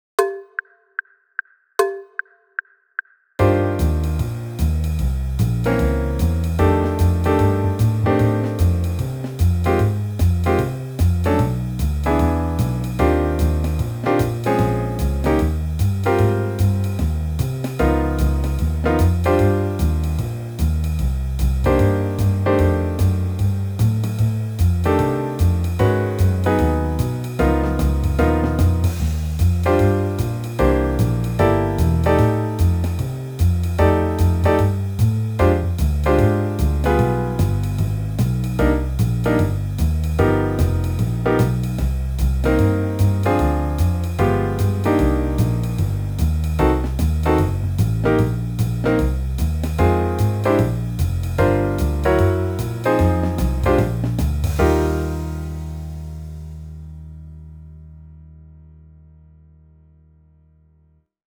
this play-along track for TENOR SAX for 2019 TMEA Jazz Sax Etude 1 was created using iReal Pro – bpm=130